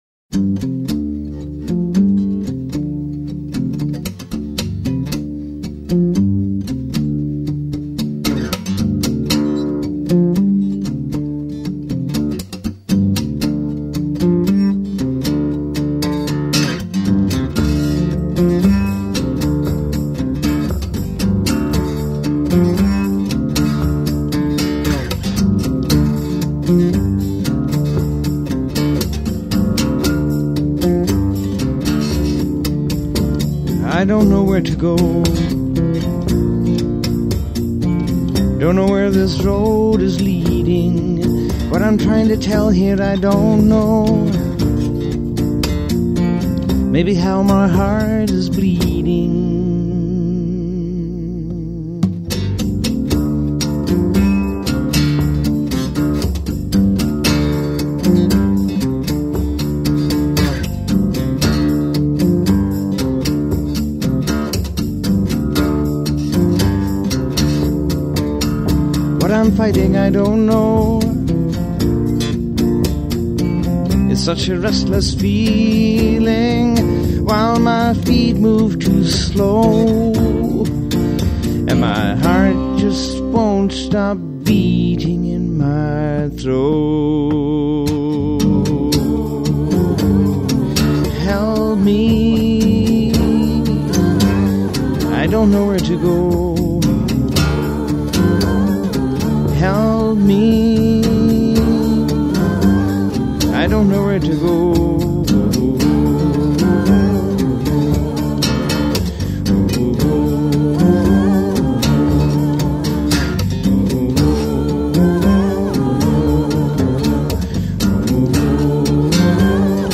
Guitars, vocals and harmonica
Bass
Percussion